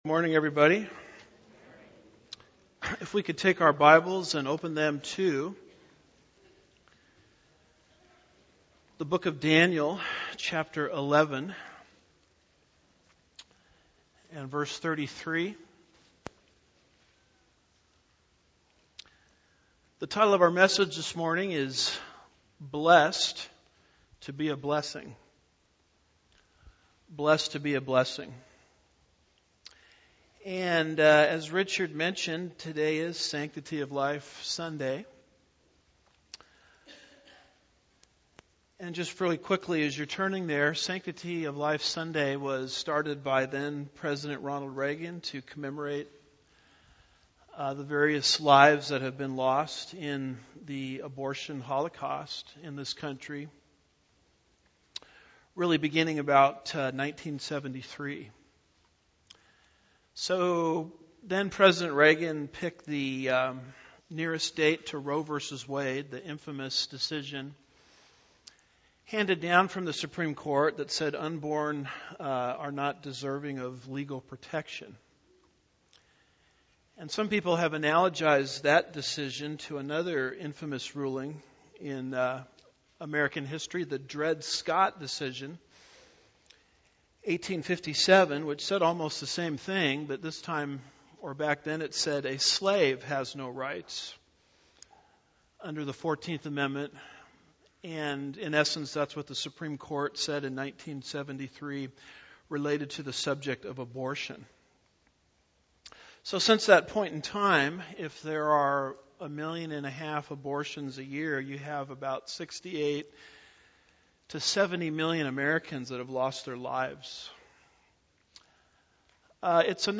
1-21-18 Daniel 11:33-35 Lesson 50